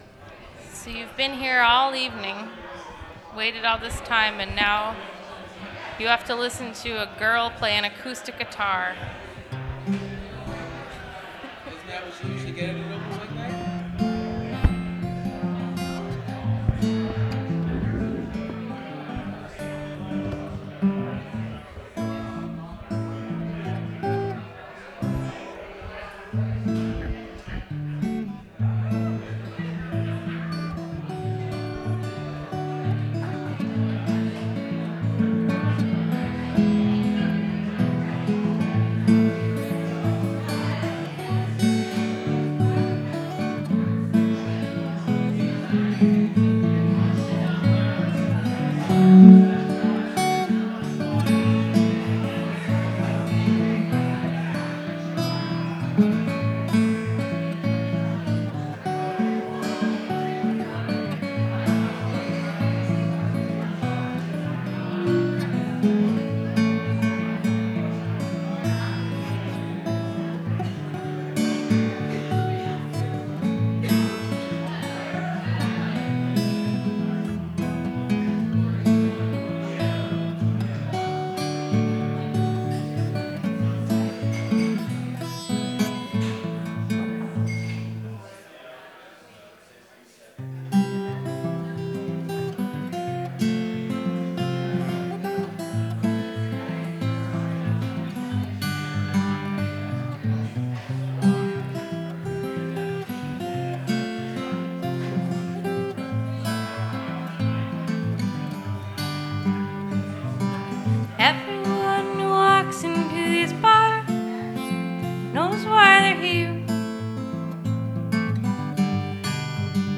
Open Mic at Crossroads Brewing
Recorded from WGXC 90.7-FM webstream.